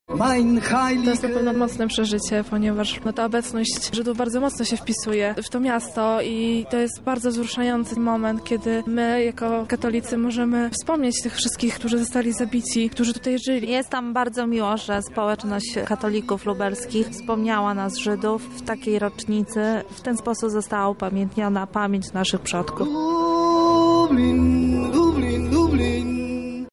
Przy Latarni Pamięci obok Bramy Grodzkiej wspólnie odmówili modlitwę oraz wysłuchali oratorium